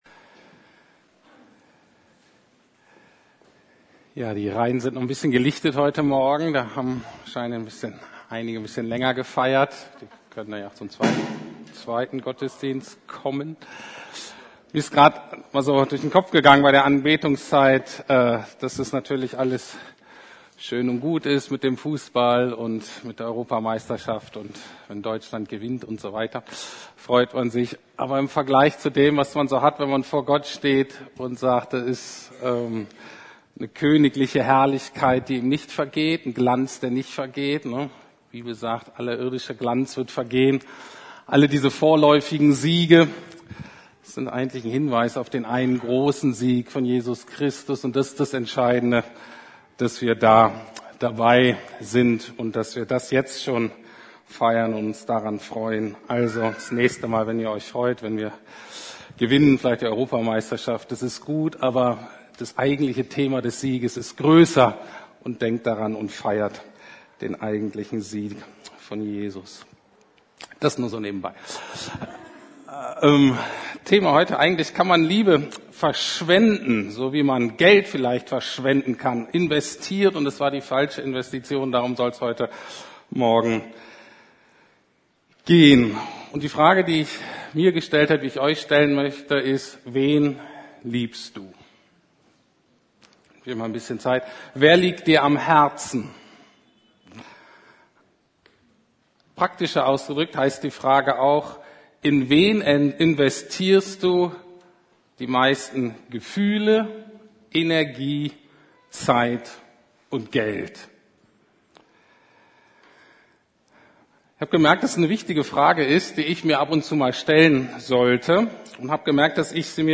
Kann man Liebe verschwenden? ~ Predigten der LUKAS GEMEINDE Podcast